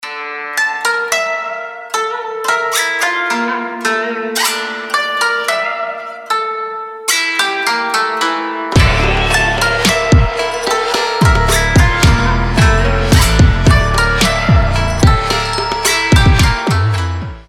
• Качество: 320, Stereo
Хип-хоп
без слов
биты